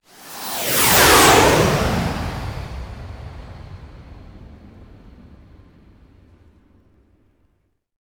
bomb.wav